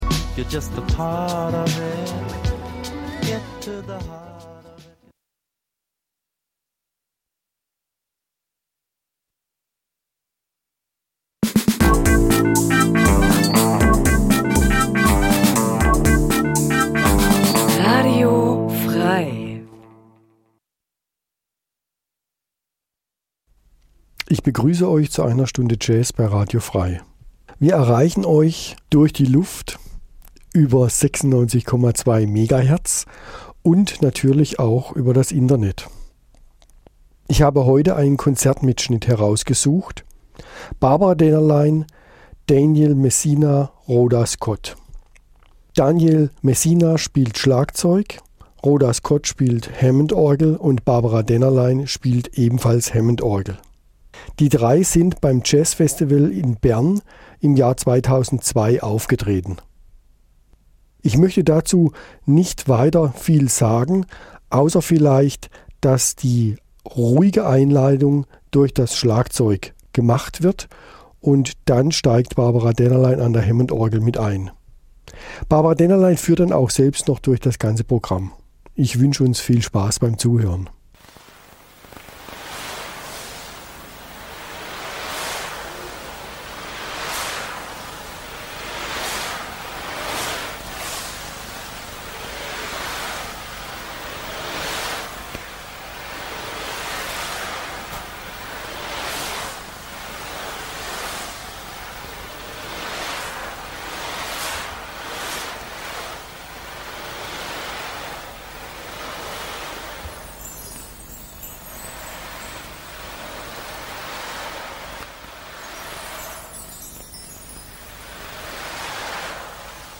Eine Stunde Jazz Dein Browser kann kein HTML5-Audio.